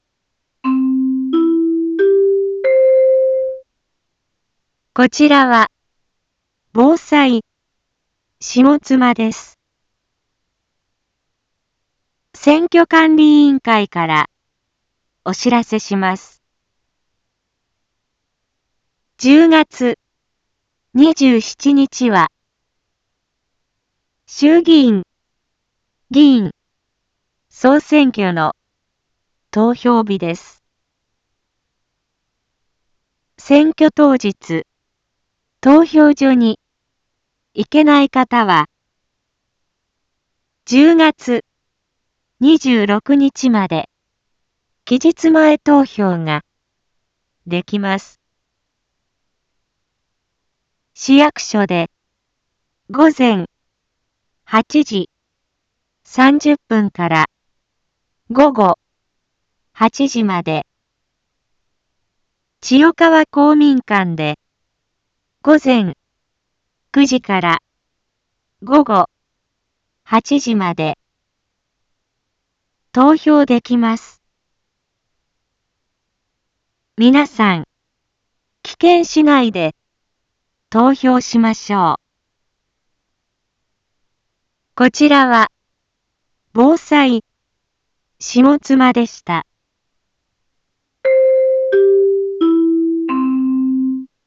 一般放送情報
Back Home 一般放送情報 音声放送 再生 一般放送情報 登録日時：2024-10-19 13:01:39 タイトル：衆議院議員総選挙の啓発（期日前投票期間） インフォメーション：こちらは、ぼうさいしもつまです。